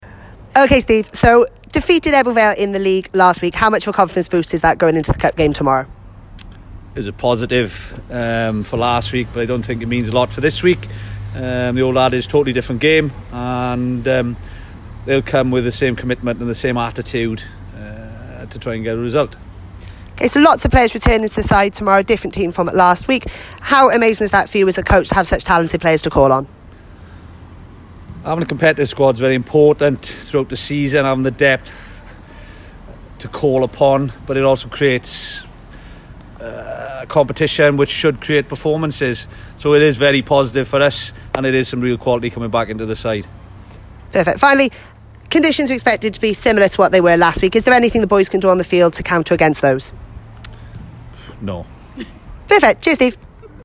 Pre Game Interview